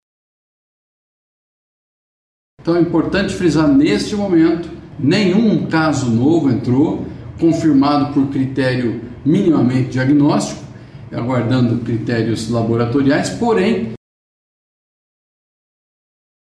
O secretário de estado da Saúde do Paraná, Beto Preto, destacou a manutenção dos três casos confirmados de intoxicação, sem novos registros.